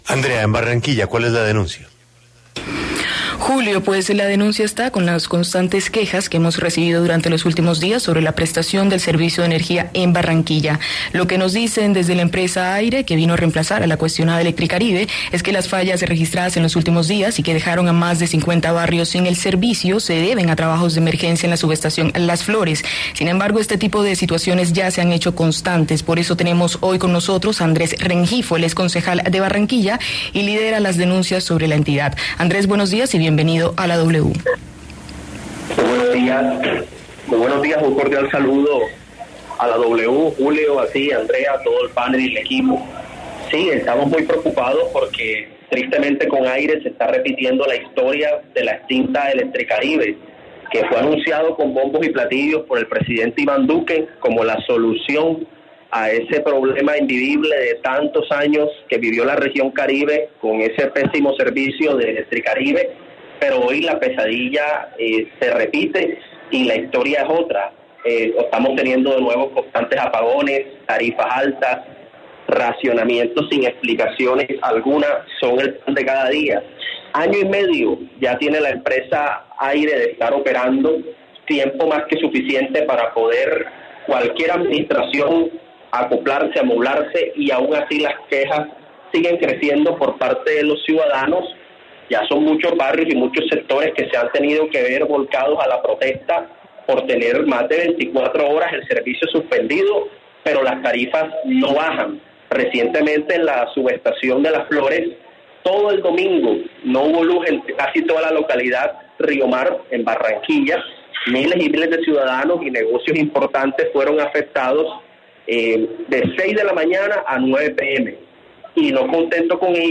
En diálogo con La W, el concejal Andrés Rengifo se refirió a las problemáticas que se han estado presentando con la empresa de energía Air-e y que señala, han repetido los episodios que se vivían con Electricaribe.